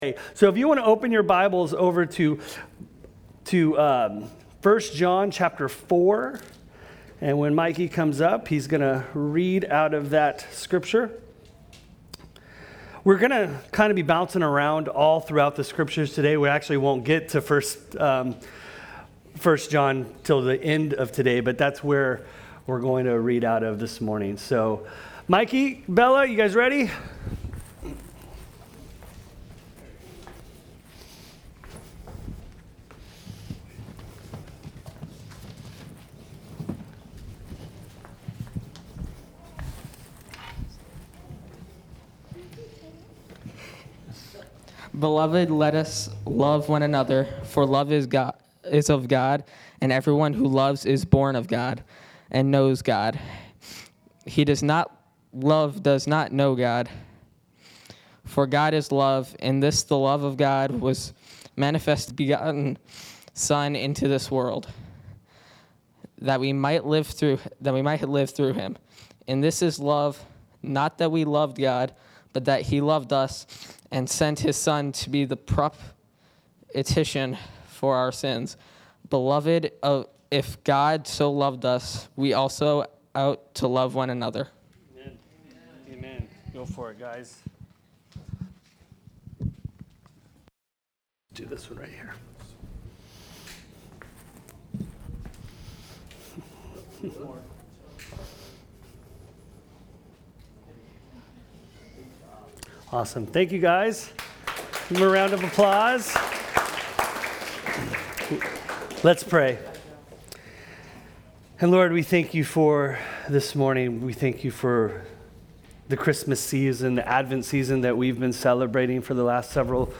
Sermon Series – Calvary Chapel West Ashley